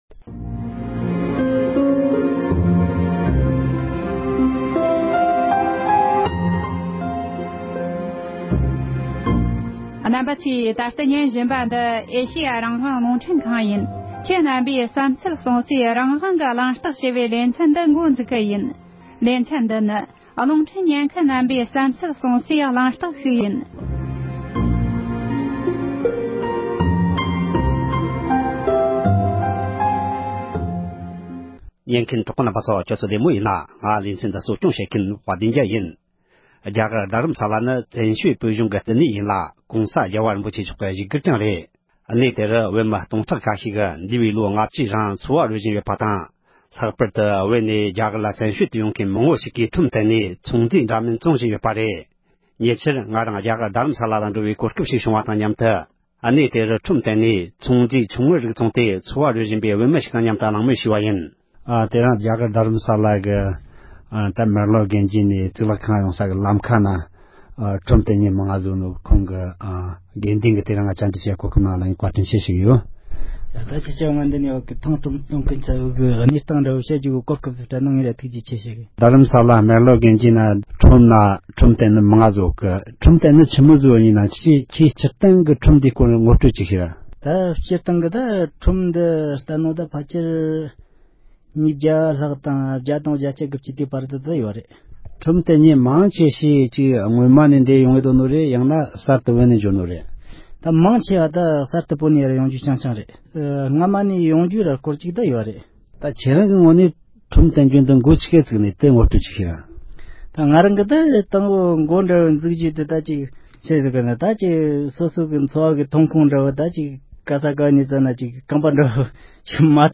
གླེང་མོལ་ཞུས་པ།